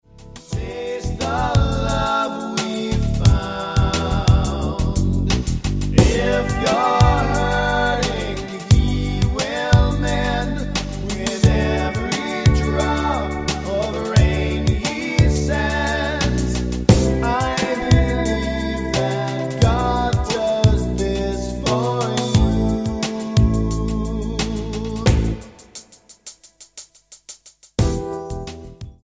STYLE: Pop
a gutsy blues rock voice
the eerily haunting piece of funky prog rock